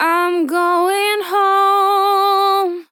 I'm going home 2 Vocal Sample
Categories: Vocals Tags: DISCO VIBES, dry, english, female, going, home, Im, LYRICS, sample